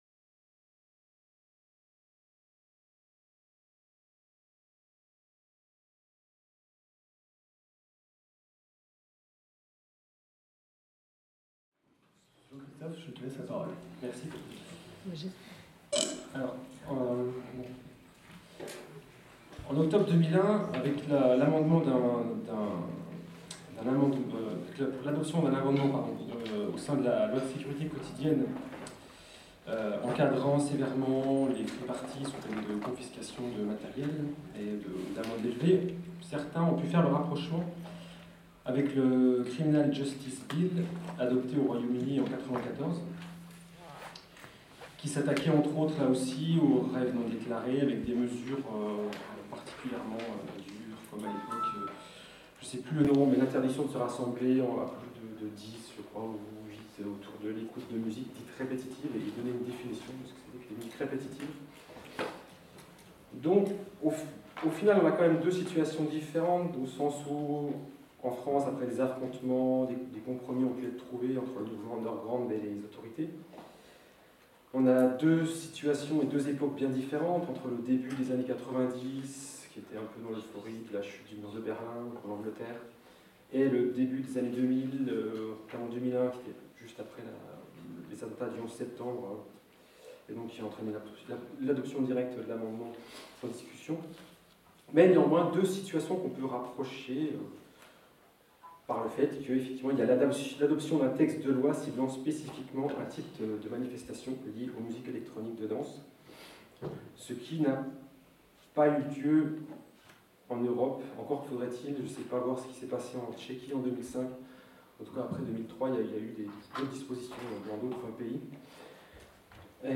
Intervention